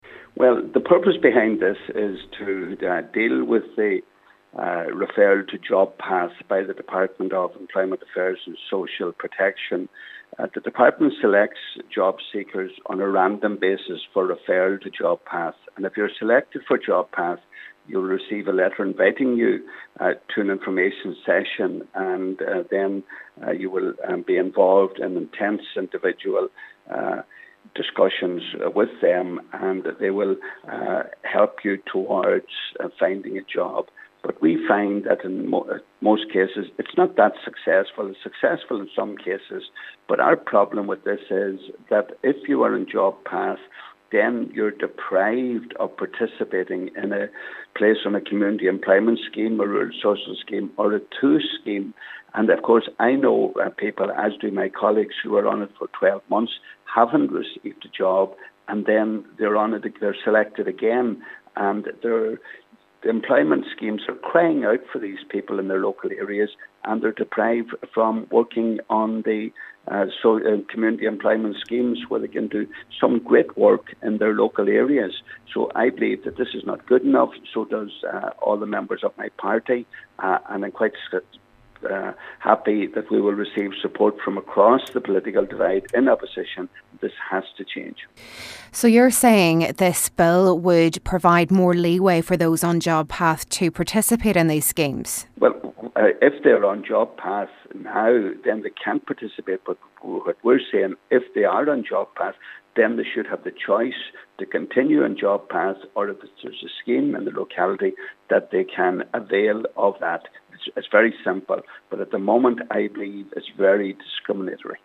Donegal Deputy Pat the Cope Gallagher says people should have a choice: